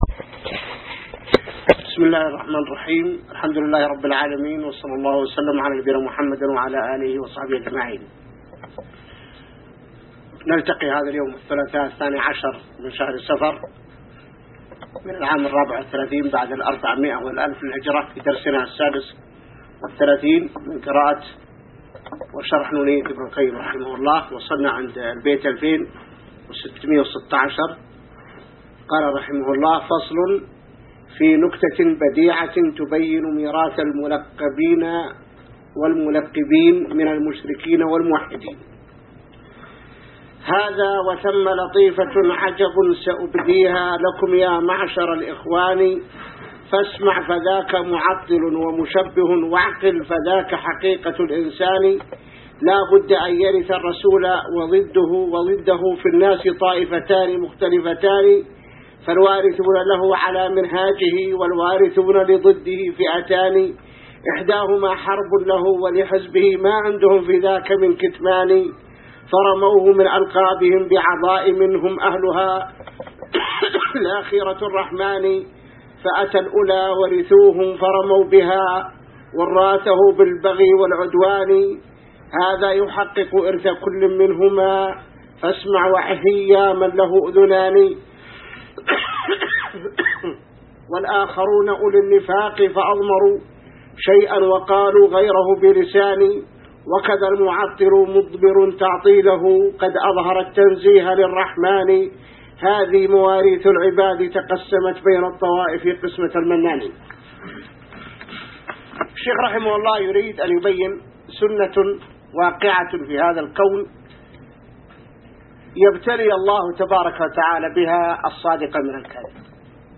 الدرس 36 من شرح نونية ابن القيم | موقع المسلم